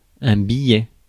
Ääntäminen
IPA: [bi.jɛ]